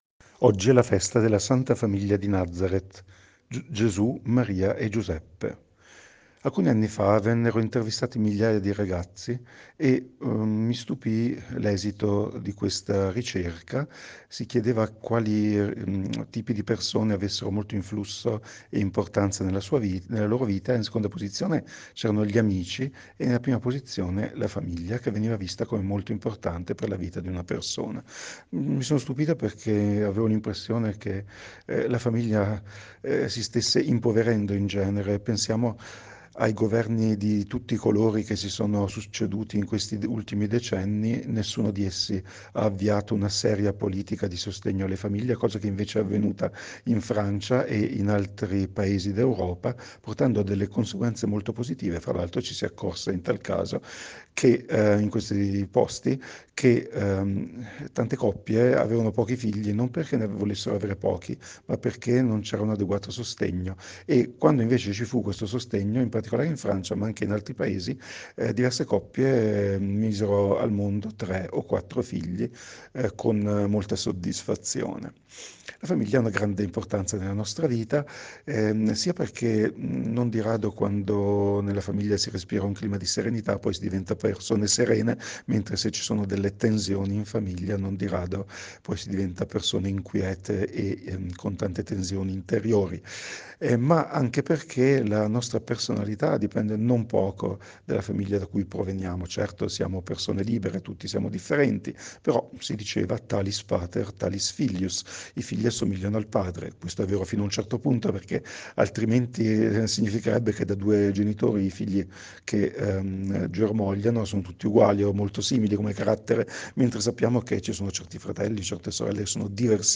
Meditazione Domenica 31 Dicembre 2023 – Parrocchia di San Giuseppe Rovereto